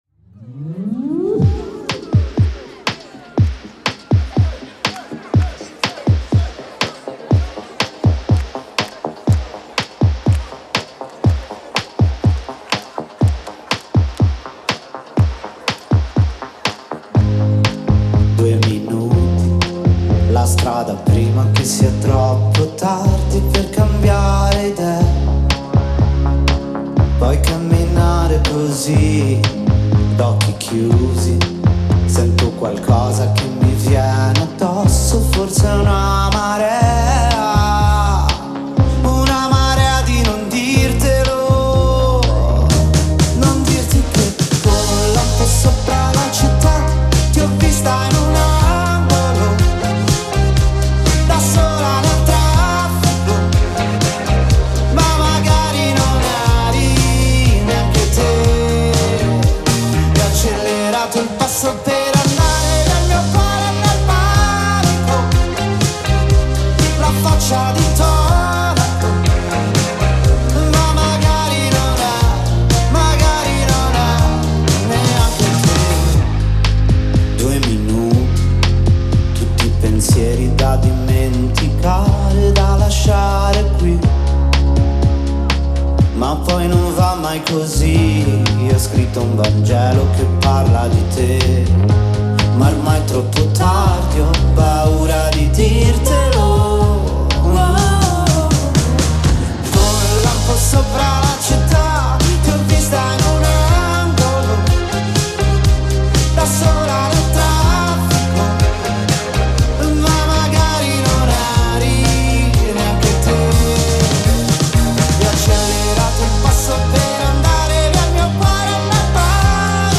a little upbeat never hurt anybody.